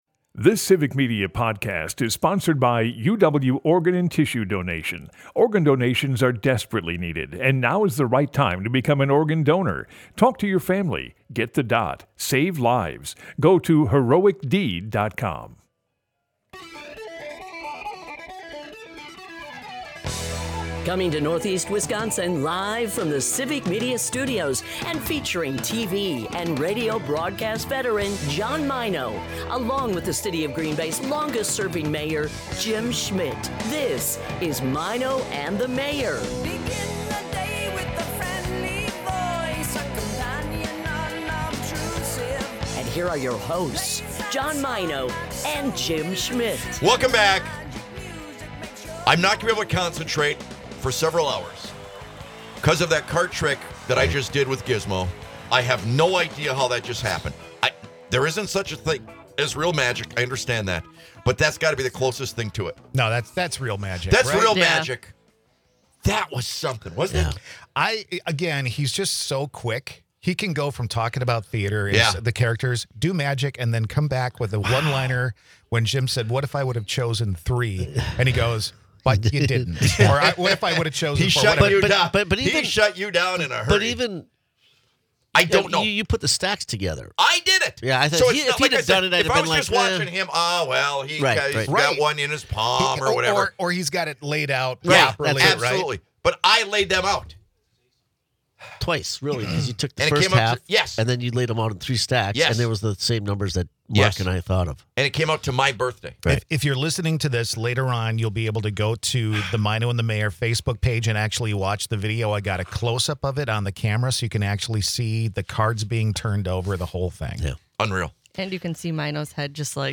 Broadcasts live 6 - 9am in Oshkosh, Appleton, Green Bay and surrounding areas.